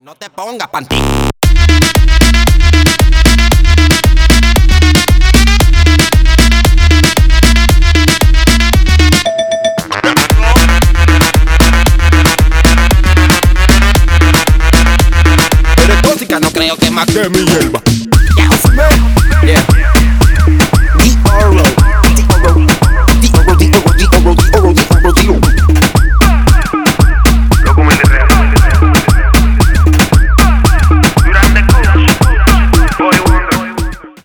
Танцевальные
клубные # ритмичные